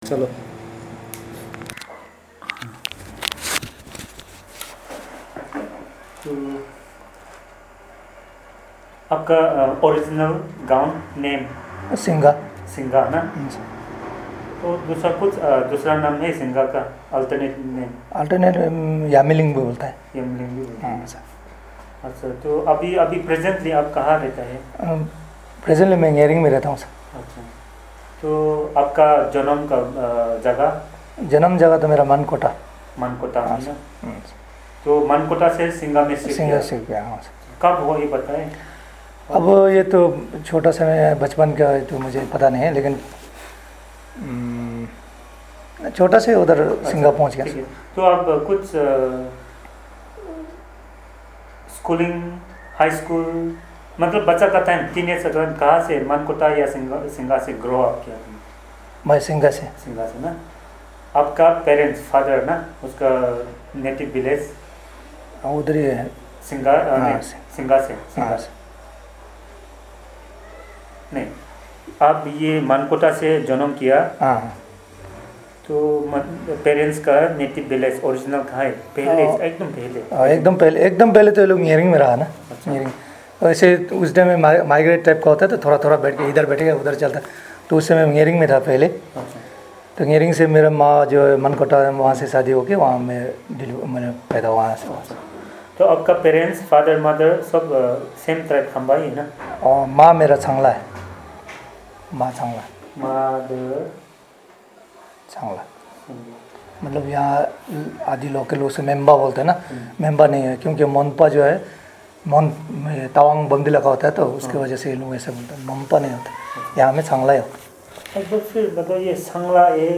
Personal narrative